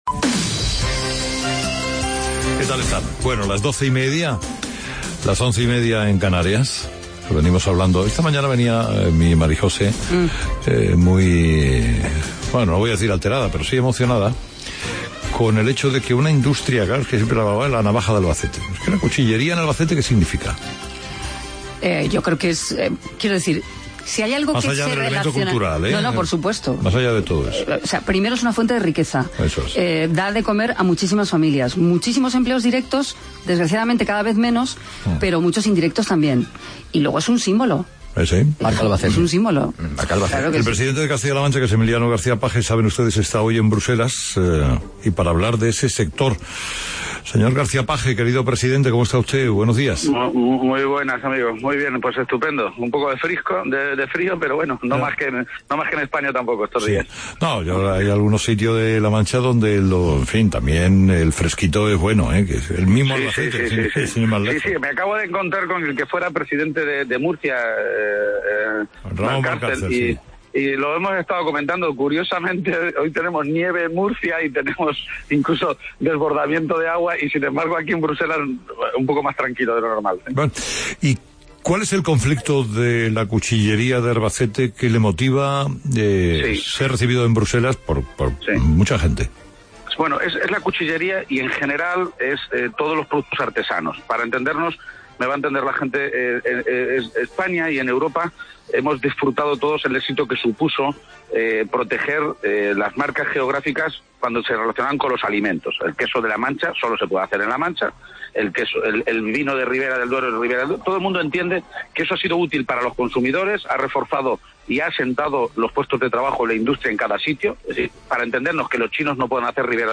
170125 Entrevista Carlos Herrera con García Page
Escucha la entrevista que Carlos Herrera le ha realizado al presidente García Page con la cuchillería de Albacete como argumento y su viaje a Bruselas.